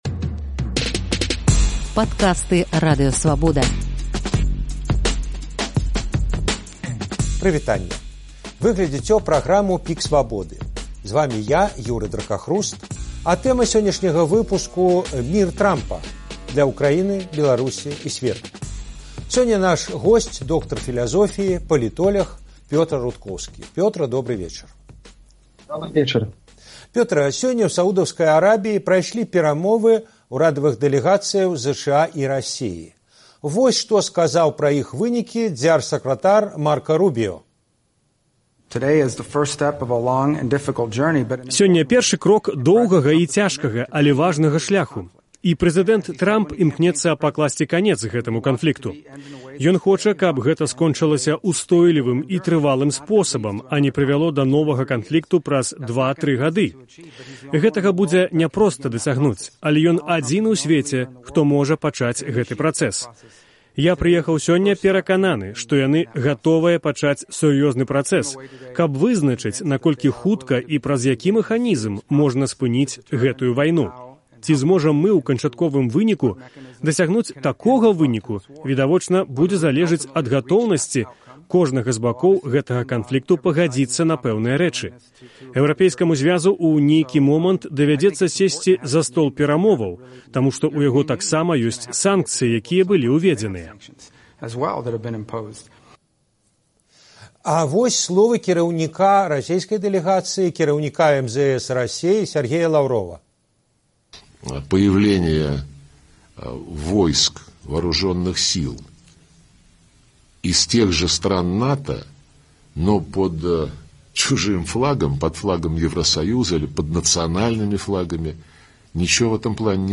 адказвае доктар філязофіі, палітоляг